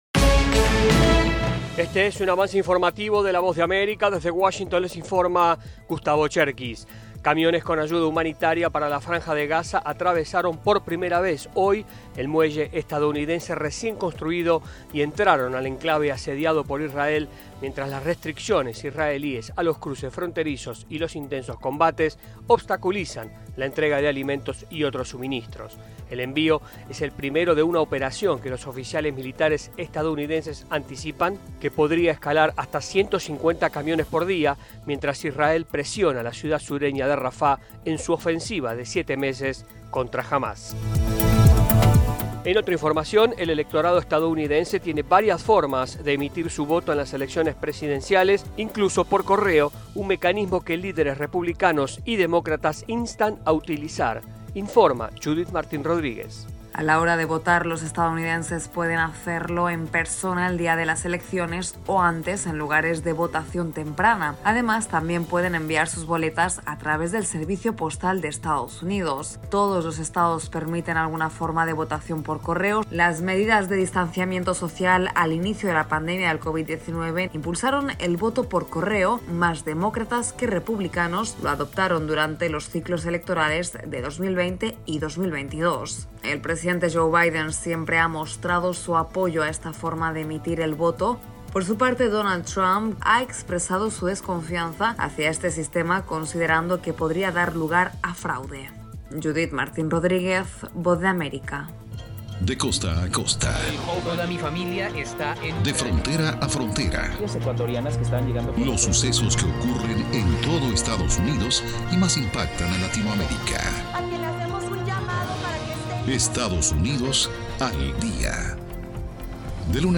Avance Informativo
Este es un avance informativo de la Voz de América.